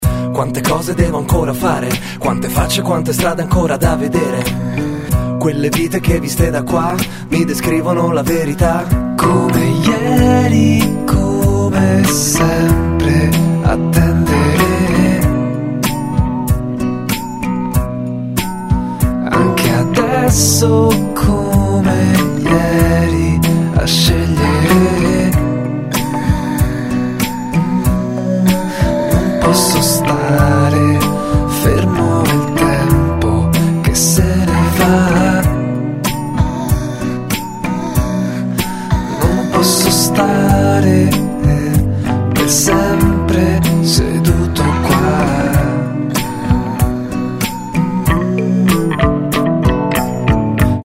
латинские
нежные